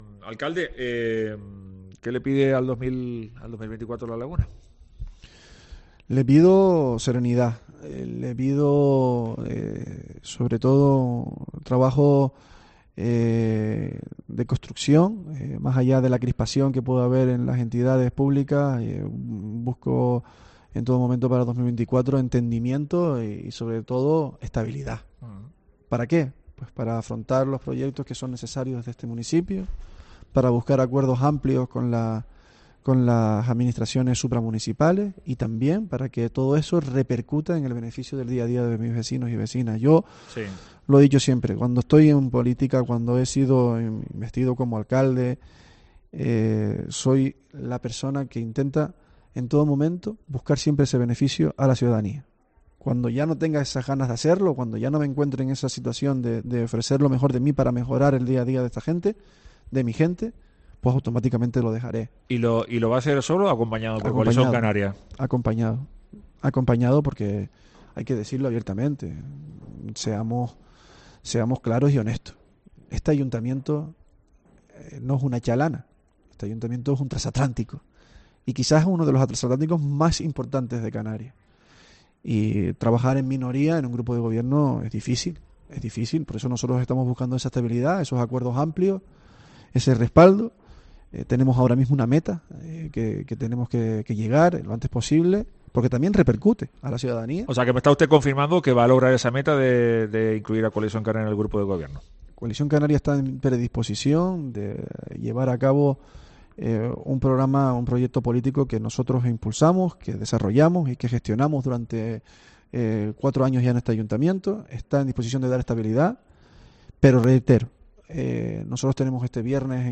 El alcalde adelanta en COPE que llevará el acuerdo esta semana a la Asamblea local de los socialistas
Así lo ha adelantado este martes el alcalde del municipio, Luis Yeray Gutiérrez, en los micrófonos de Herrera en COPE Tenerife.